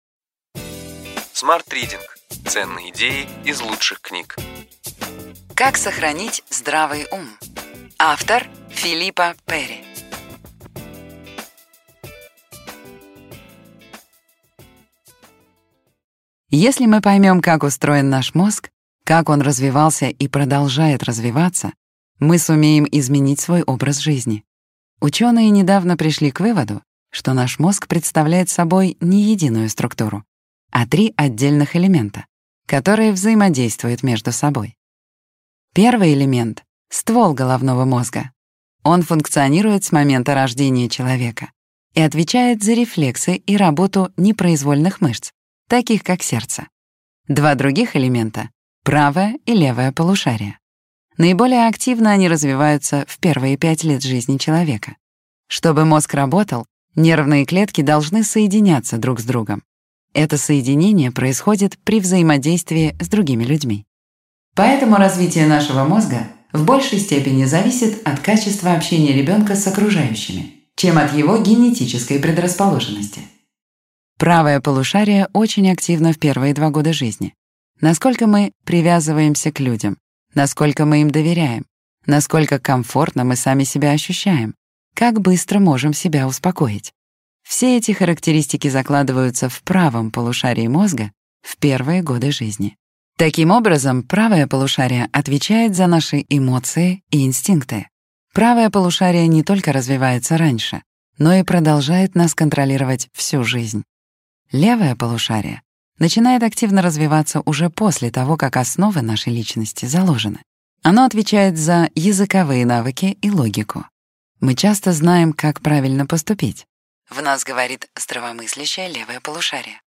Аудиокнига Ключевые идеи книги: Как сохранить здравый ум. Филиппа Перри | Библиотека аудиокниг